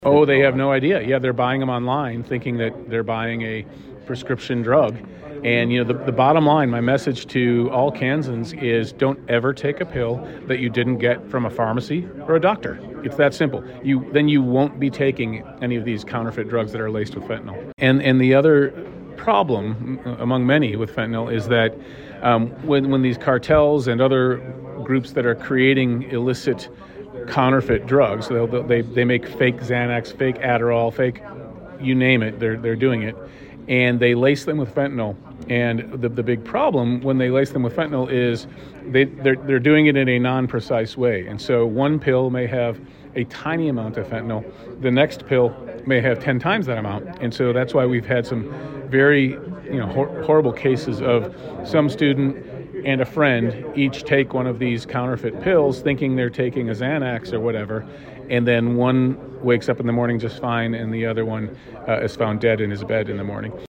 Kobach said in nearly every case the victim unknowingly ingested Fentanyl, and even a small dose can be fatal.